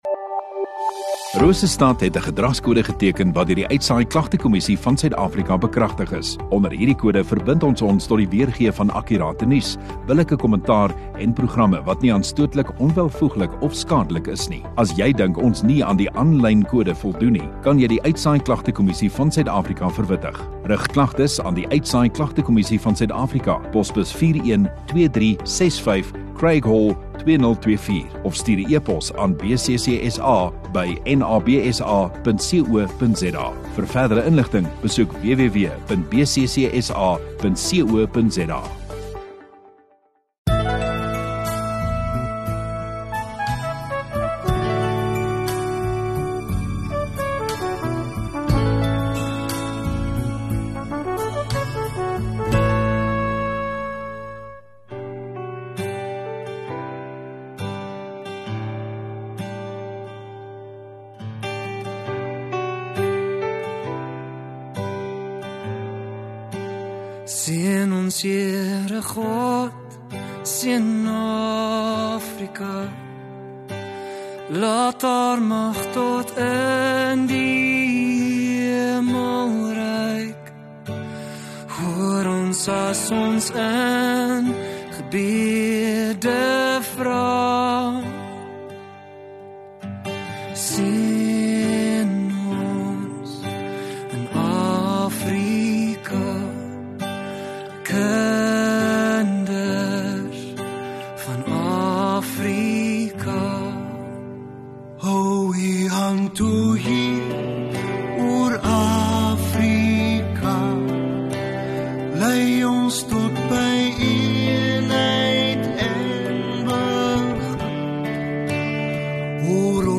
30 Jun Sondagoggend Erediens